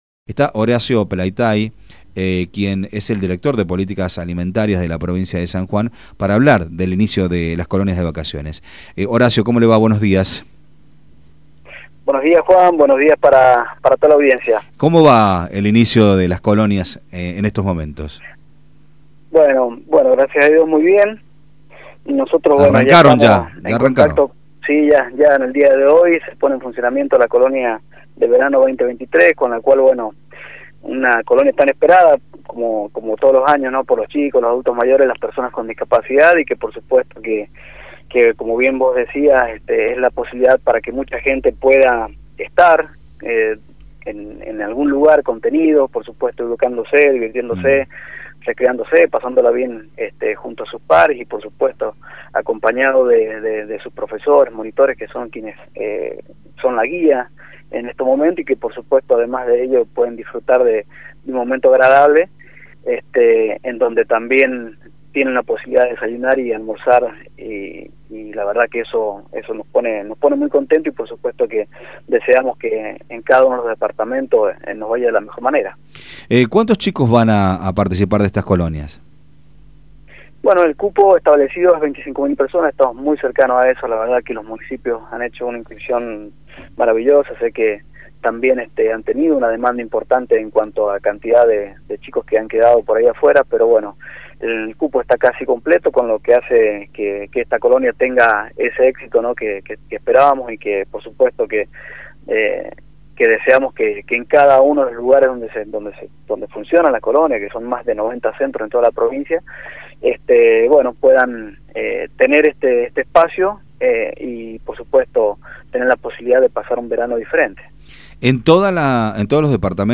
En este marco, el Director de Políticas Alimentarias, Horacio Pelaitay dialogó con Radio Sarmiento acerca de esto.